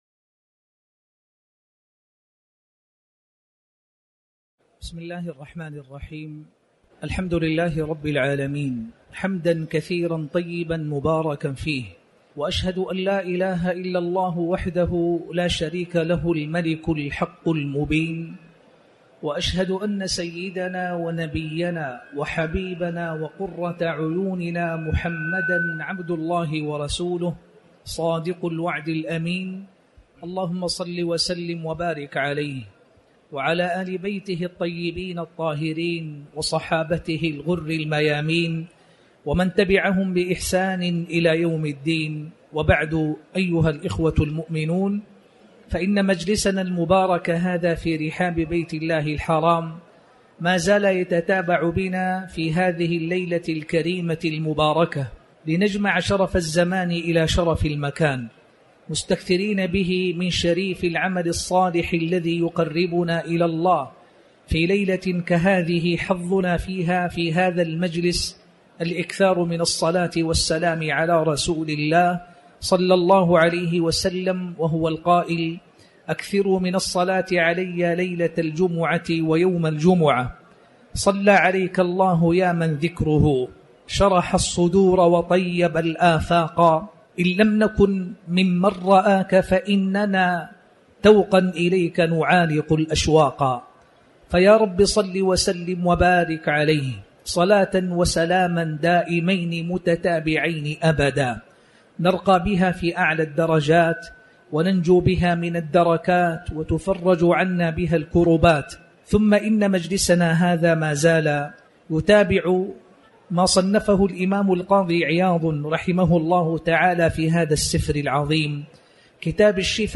تاريخ النشر ٢٢ ذو القعدة ١٤٤٠ هـ المكان: المسجد الحرام الشيخ